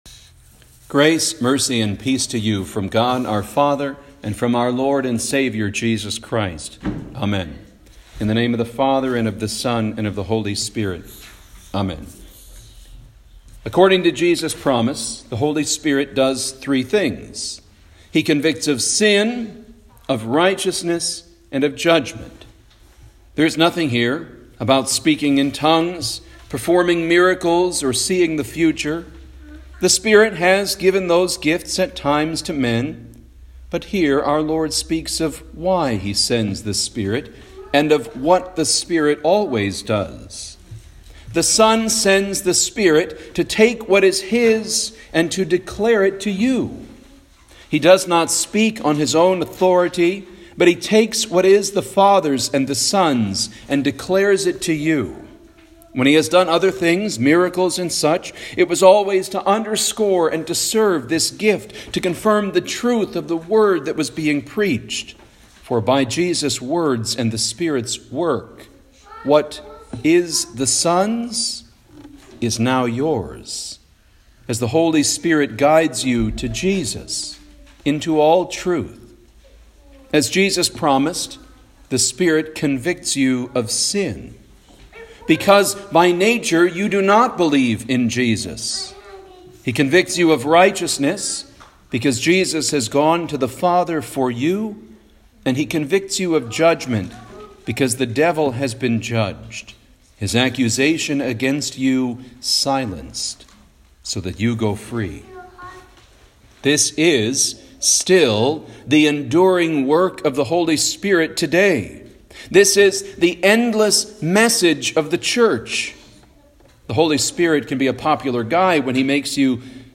Home › Sermons › Cantate, Easter 5